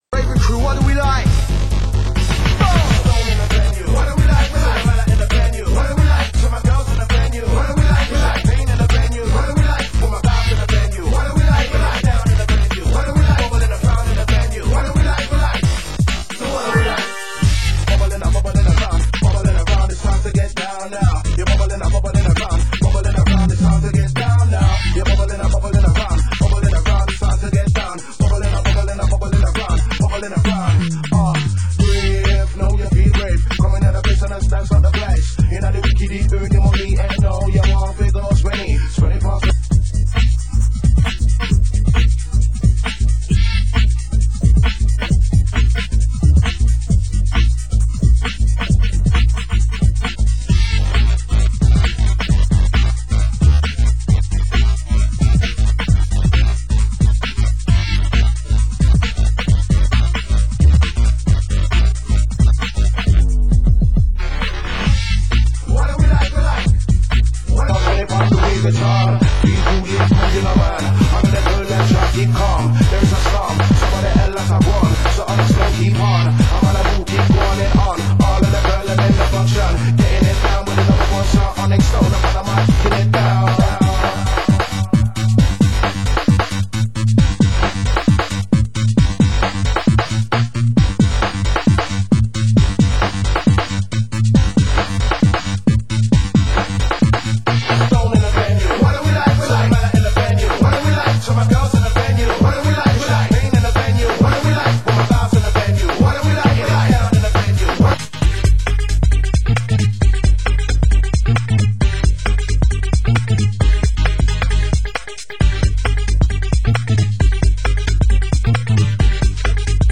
Genre: UK Garage
ORIGINAL PA INSTRUMENTAL